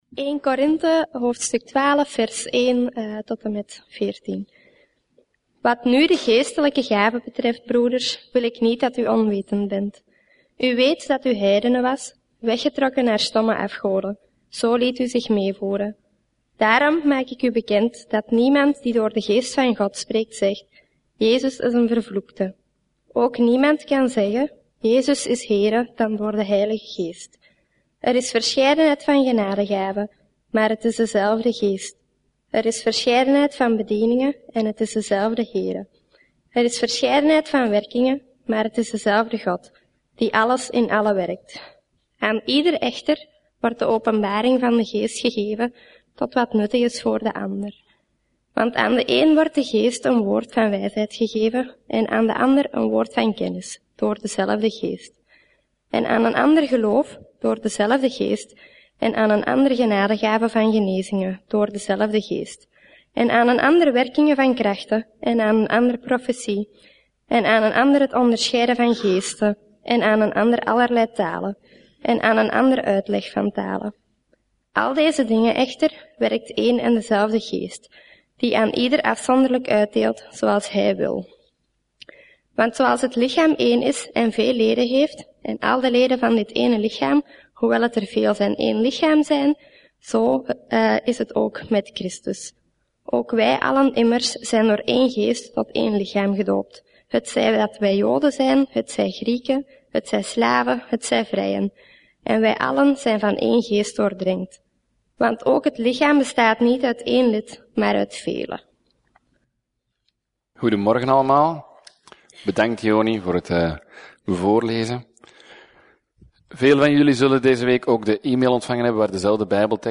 Preek: Het Lichaam van Christus – 1 van 4 - Levende Hoop